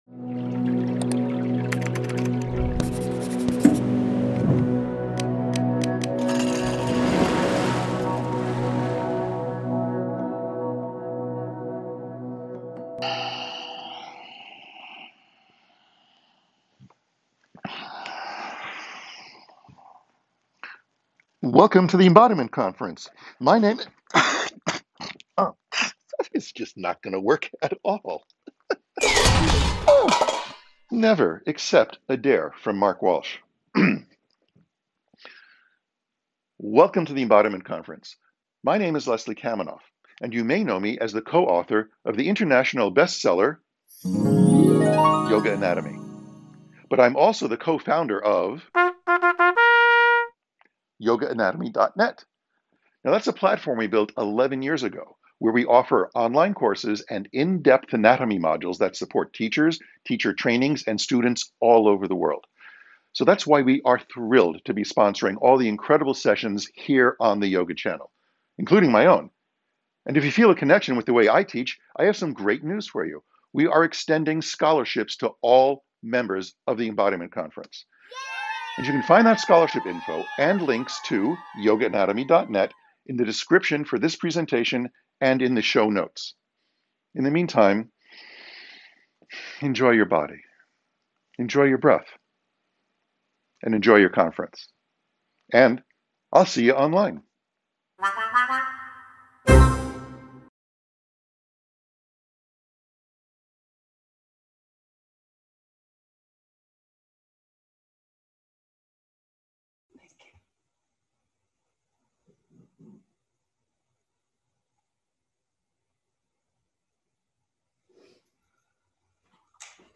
Embodying Gentleness: Yoga Practice and Meditation for Peace Intermediate understanding Space and loose clothed required Likely neutral A pre-recorded session. Expect to begin with a short centering, experience a hatha vinyasa practice aimed at steadying and gentling your systems, and conclude with a meditation focused on cultivating peace within and around you.